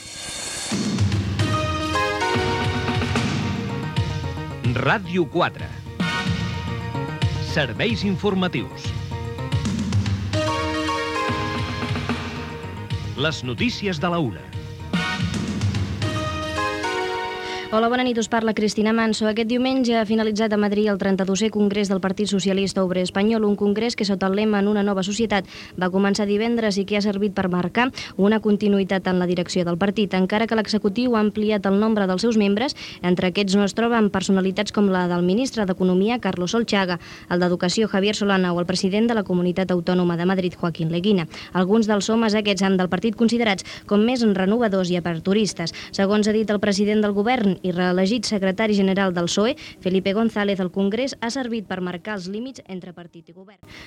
Careta del progra i informació del XXXII congrés del Partido Socialista Obrero Español
Informatiu